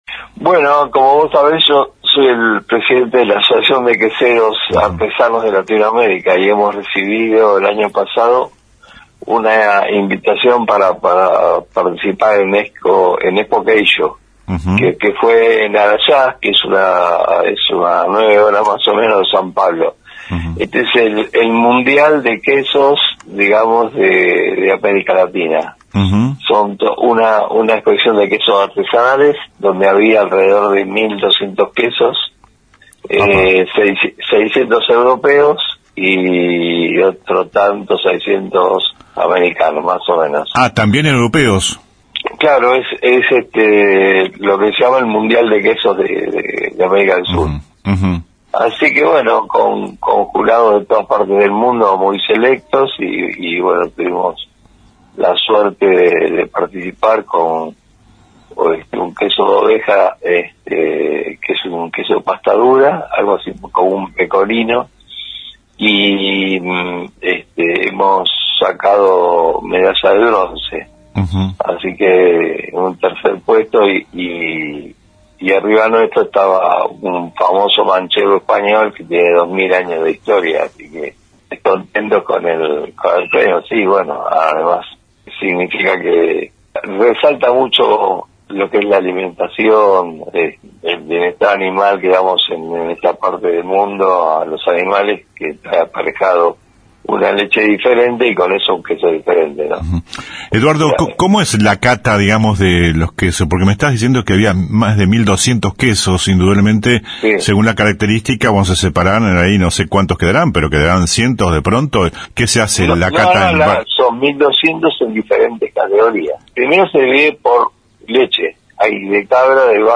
AUDIO DE LA ENTREVISTA.